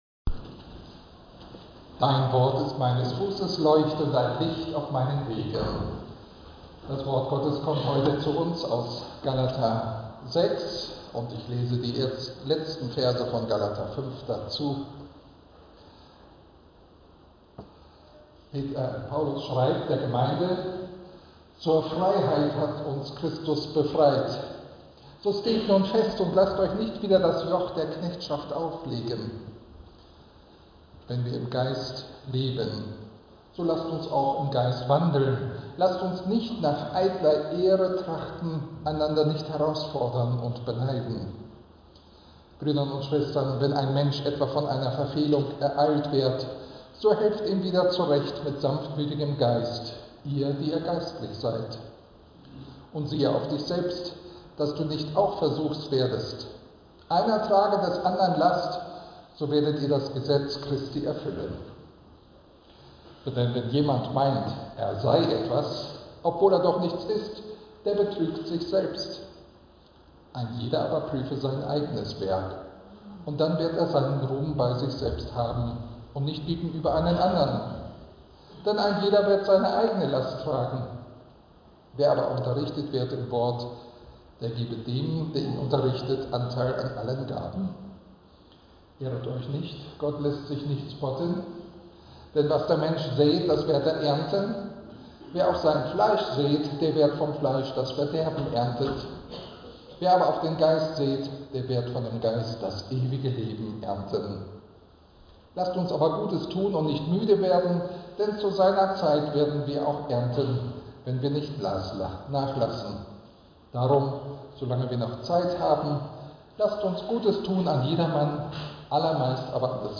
Lutherische Gemeinde Lüneburg Lutheran Congregation - Sermon Predigt für den 15.